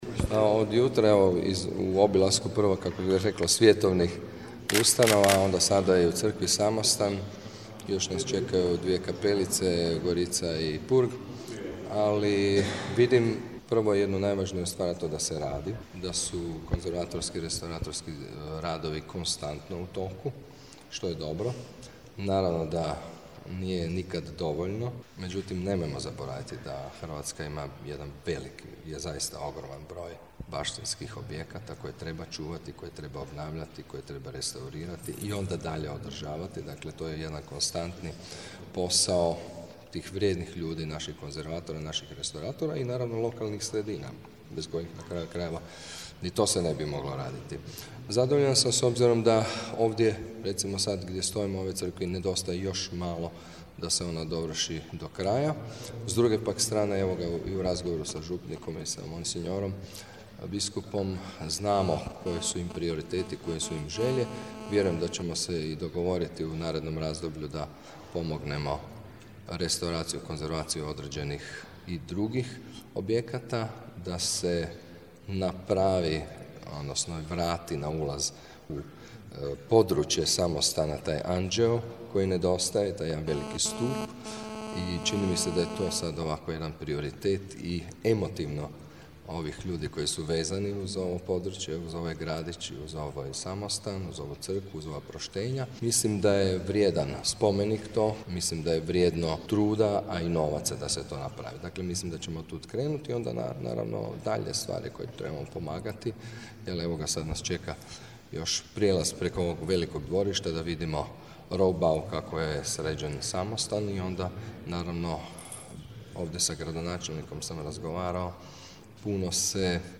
Izjava ministra kulture Berislava Šipuša